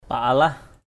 /pa-a-lah/ 1.
paalah.mp3